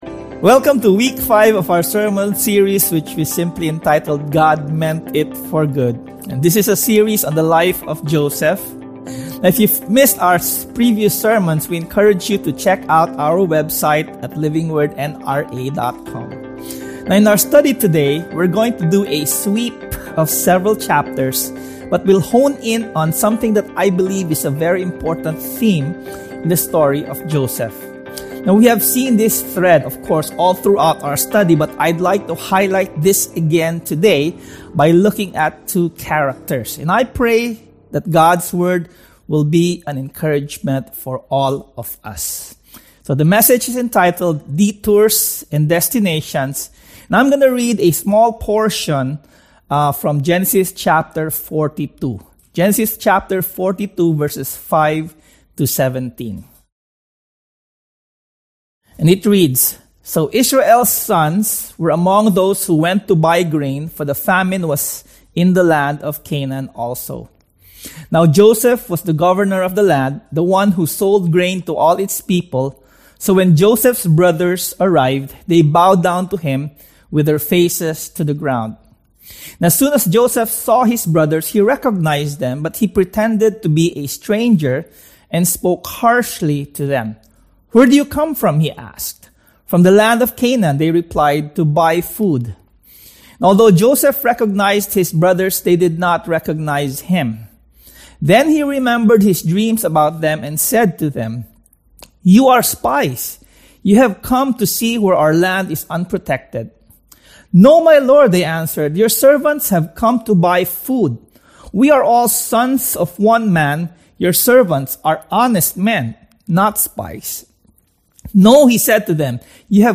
Worship God Meant It For Good Genesis Watch Listen Read Save God is concerned with the destination just as much as he is concerned with what we become when we get there. Sermon #5: Detours & Destinations Sermon Series: God Meant It For Good